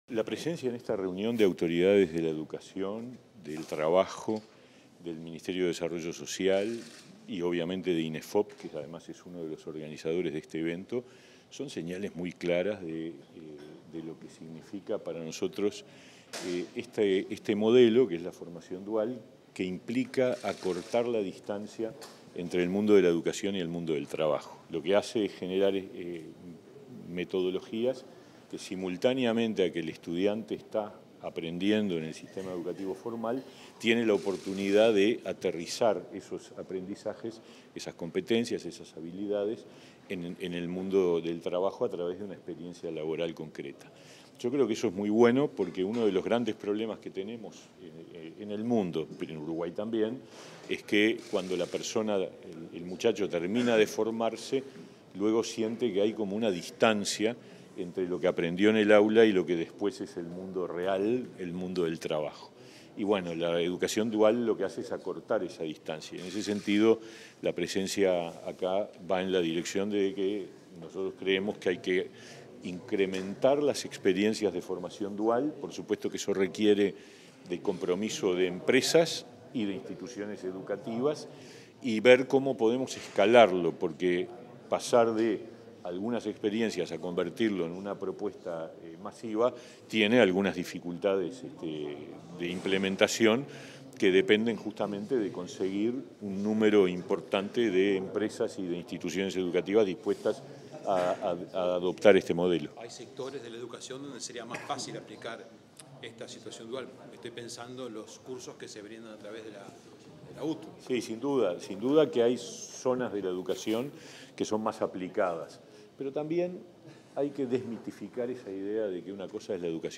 Declaraciones del ministro de Trabajo y Seguridad Social, Pablo Mieres
Declaraciones del ministro de Trabajo y Seguridad Social, Pablo Mieres 24/05/2023 Compartir Facebook X Copiar enlace WhatsApp LinkedIn Tras participar en la primera edición del evento Red de Formación Dual en Uruguay, este 24 de mayo, el ministro de Trabajo y Seguridad Social, Pablo Mieres, realizó declaraciones a la prensa.